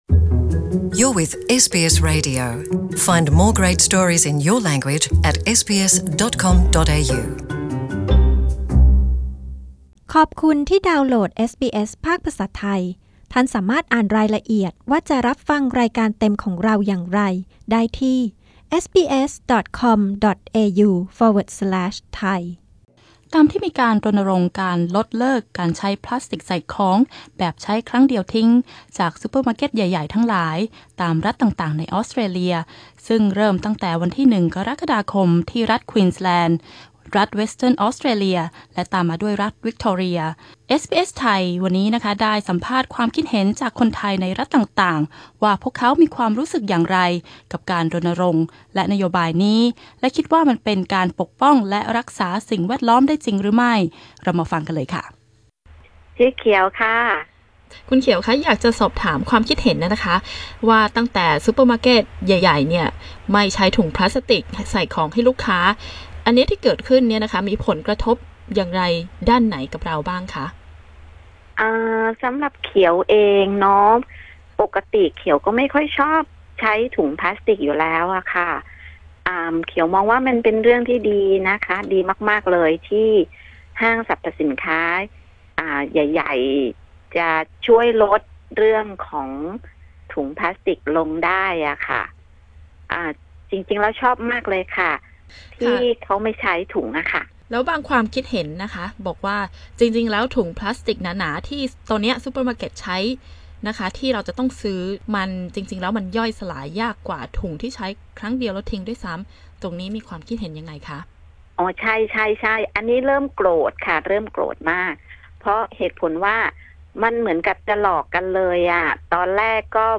รัฐต่างๆในออสเตรเลีย เริ่มมีการรณรงค์ลดการใช้ถุงพลาสติกแบบใช้ครั้งเดียวทิ้ง ซึ่งเริ่มตั้งแต่ วันที่ 1 กรกฏาคมศกนี้ เอสบีเอส ไทย ได้สัมภาษณ์ความคิดเห็นจากคนไทยในรัฐต่างๆว่าพวกเขามีความรู้สึกอย่างไรกับเรื่องนี้ และพวกเขารู้สึกว่ามันเป็นการปกป้องและรักษาสิ่งแวดล้อมได้จริงหรือไม่ มาติดตามฟังกันค่ะ